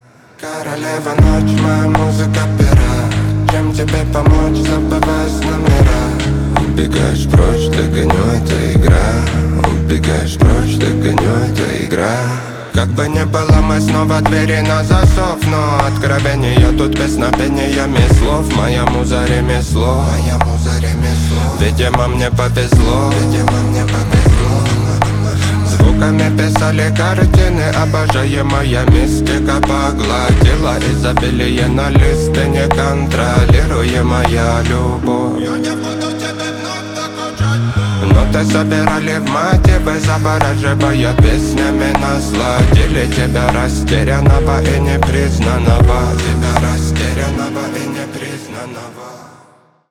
Рэп рингтоны, Рингтоны на Любимую